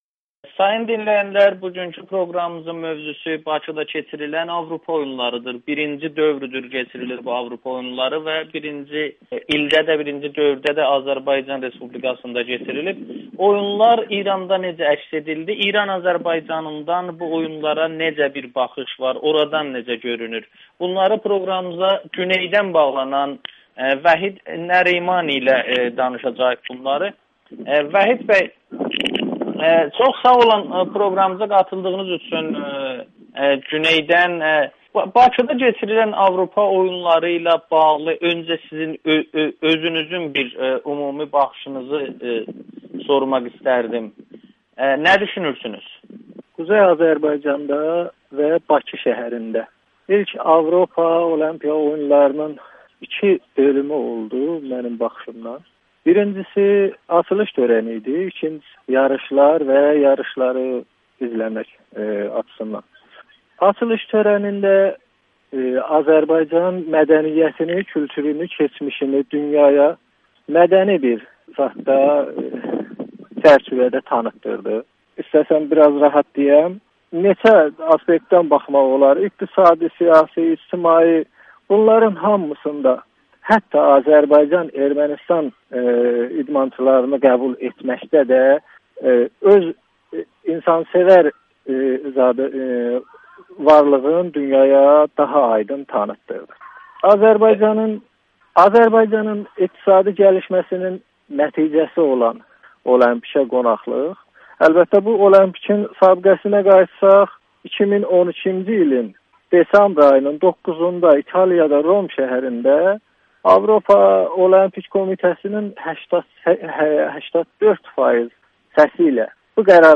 Güneyli fəal Avropa Oyunları haqda danışır [Audio-Müsahibə]